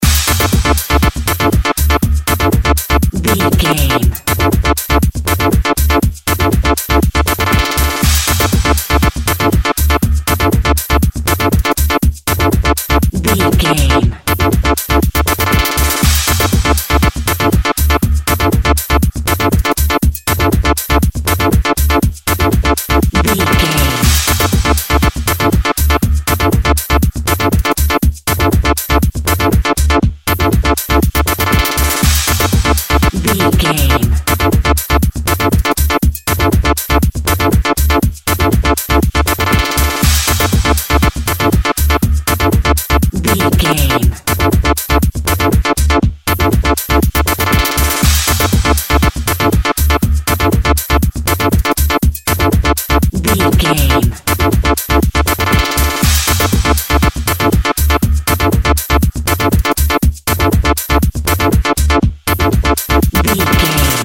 Atonal
D
Fast
futuristic
hypnotic
industrial
dreamy
frantic
aggressive
drum machine
synthesiser
house
techno
trance
electro house
synth drums
synth leads
synth bass